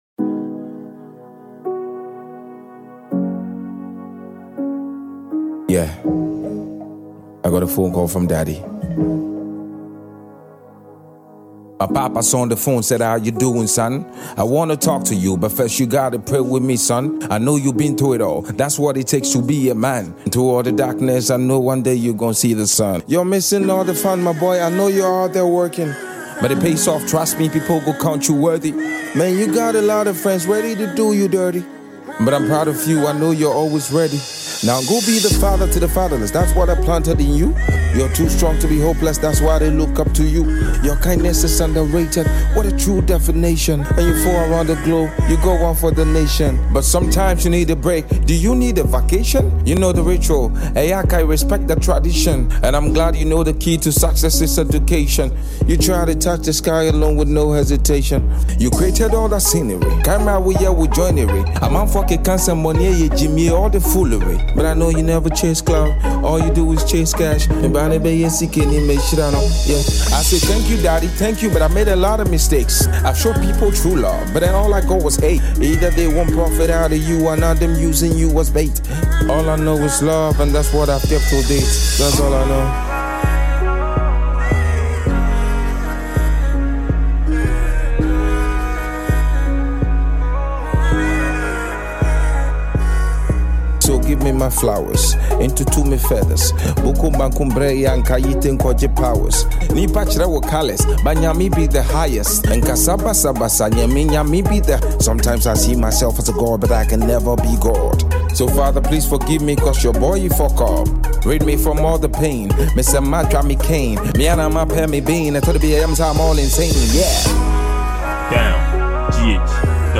Ghana’s Highlife songwriter